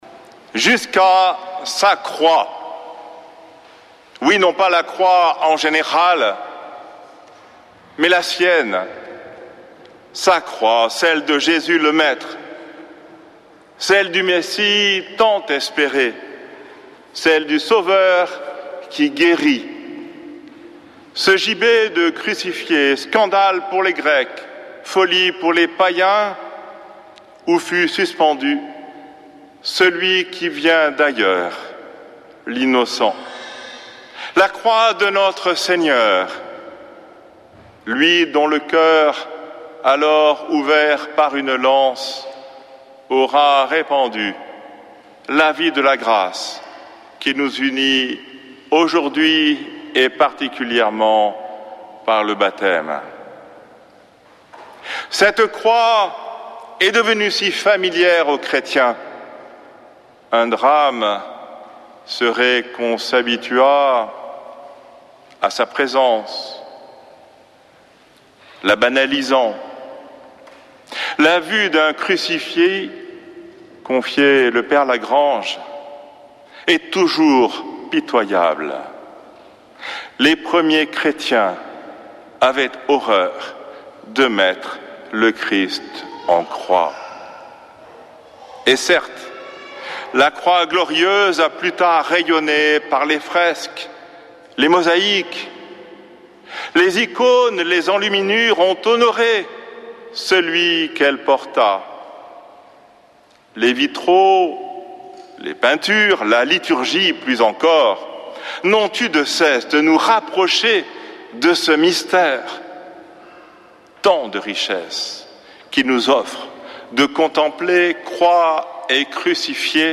dimanche 14 septembre 2025 Messe depuis le couvent des Dominicains de Toulouse Durée 01 h 28 min